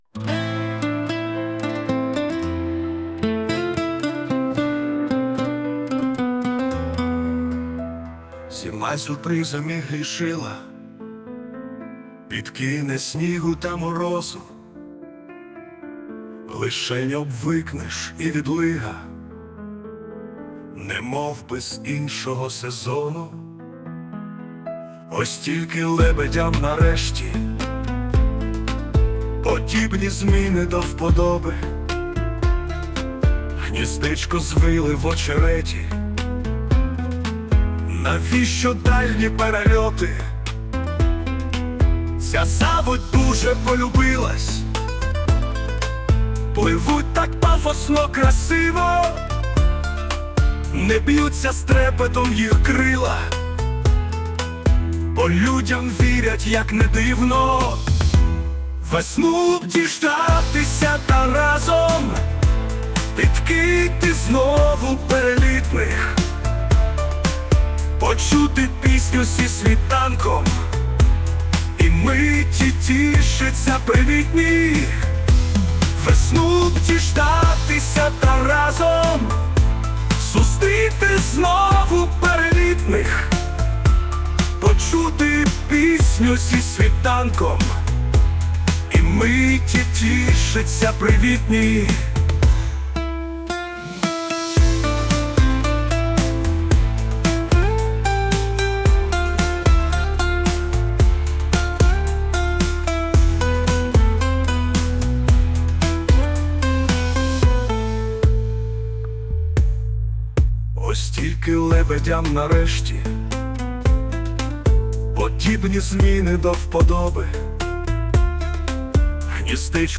Музична композиція створена за допомогою ШІ
СТИЛЬОВІ ЖАНРИ: Ліричний